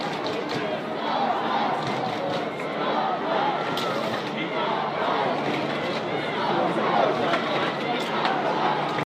Gold Coast Suns fans sing out a war cry
Suns fans singing their hearts out for the Suns in the first quarter against the Saints